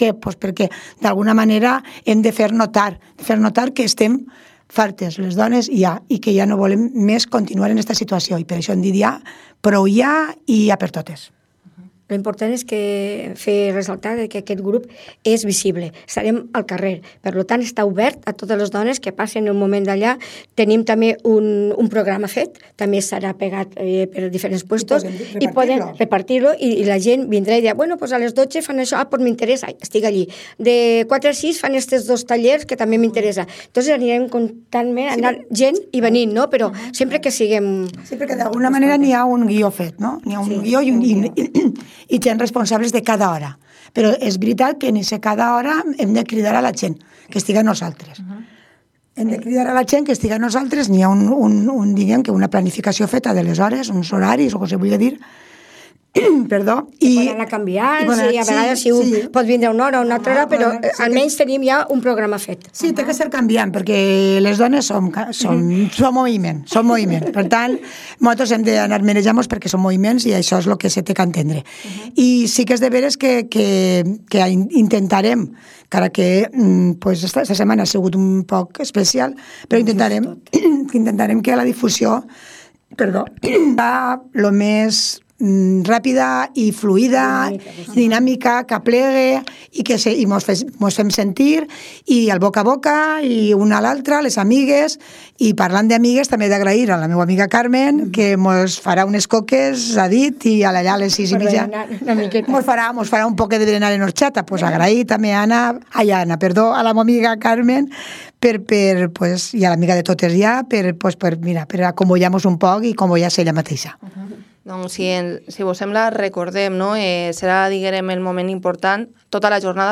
Hoy en Protagonistes hemos hablado con la alcaldesa de Les Alqueries, Esther Lara, acerca de los dos años de gobierno en la localidad que está a punto de arrancar la fiesta de la Segregación.